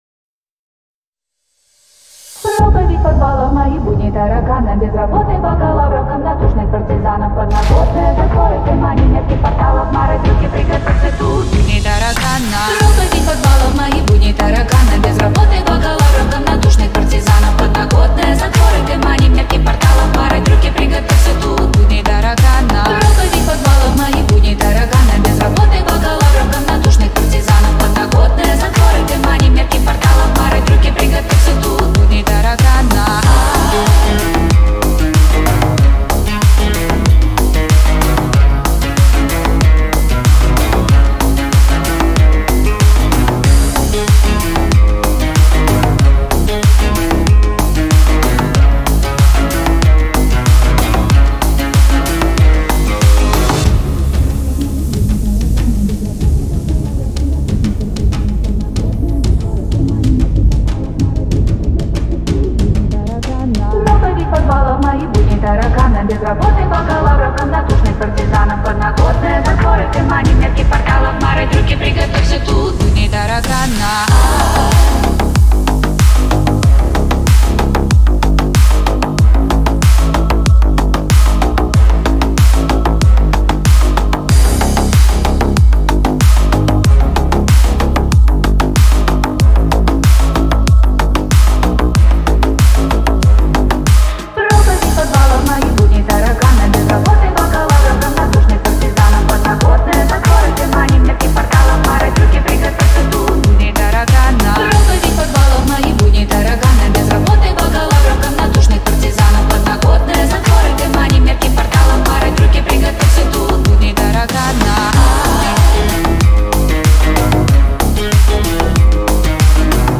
• Ремикс